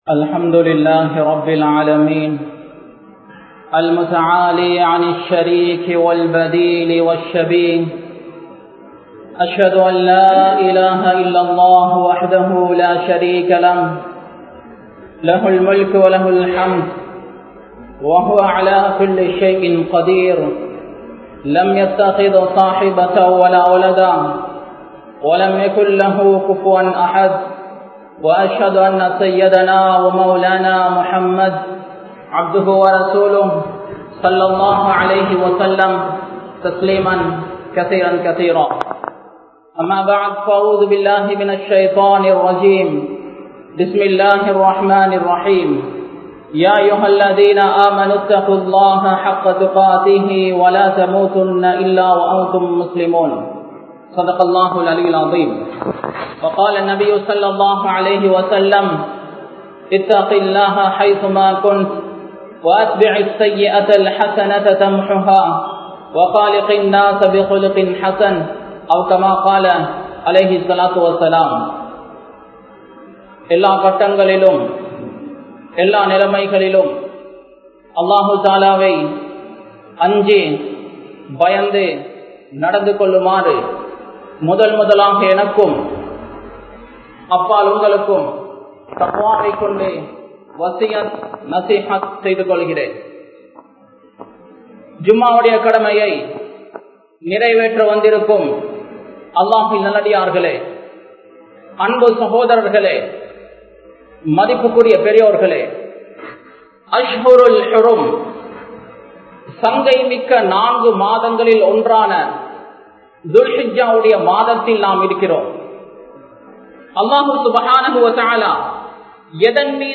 Ulhiya (உழ்ஹிய்யா) | Audio Bayans | All Ceylon Muslim Youth Community | Addalaichenai
Kandauda Jumua Masjidh